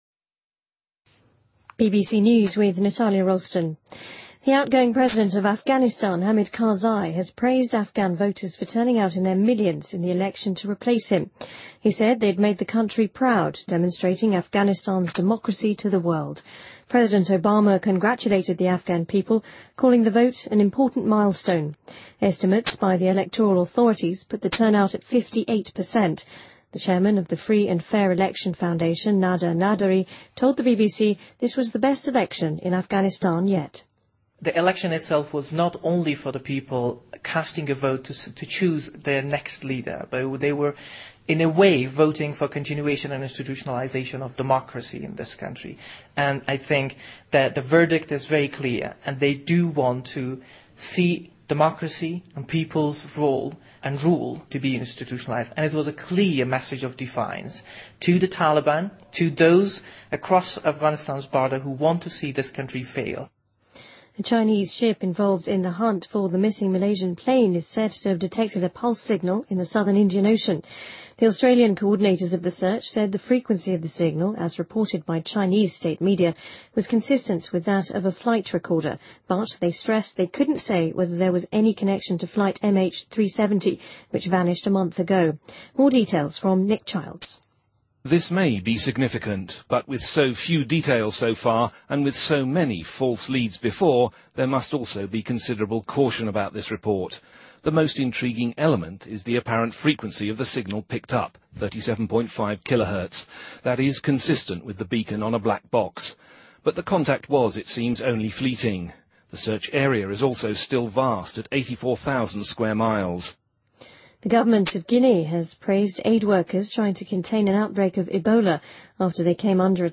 BBC news,2014-04-06